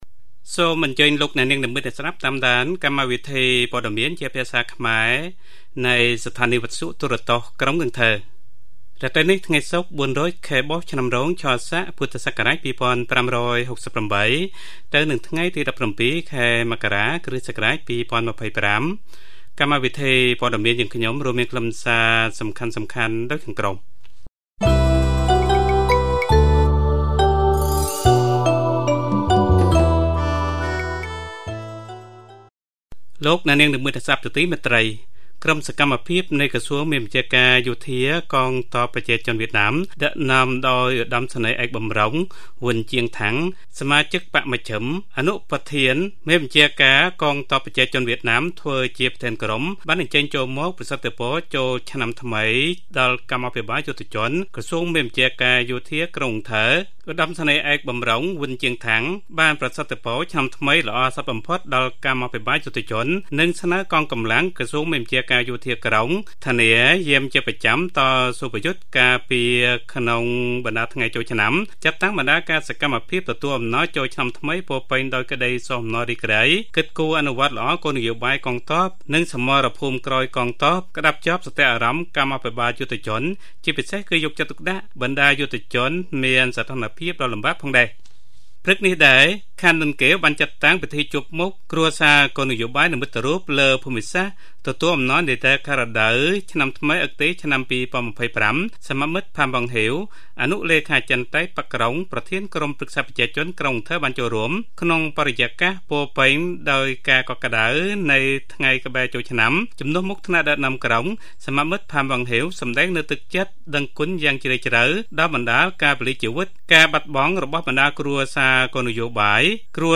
Bản tin tiếng Khmer tối 17/1/2025